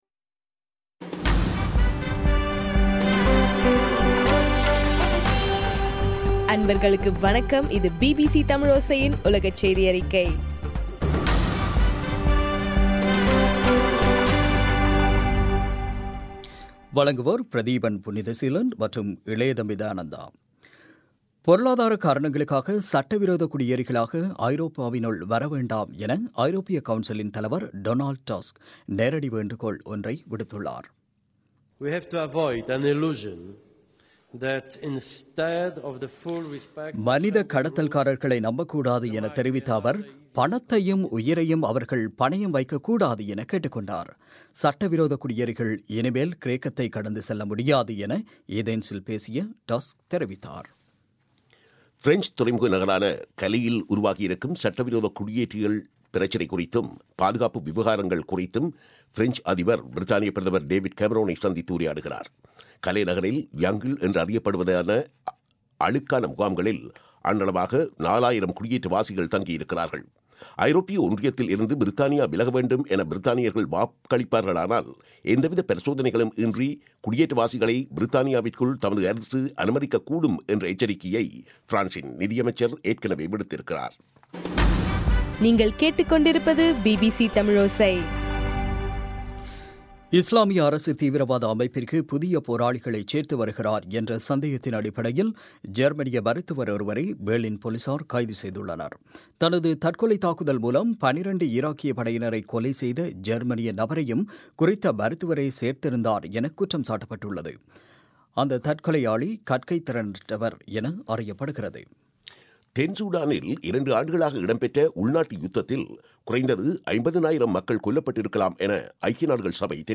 பிபிசி தமிழோசை- உலகச் செய்தியறிக்கை- மார்ச் 03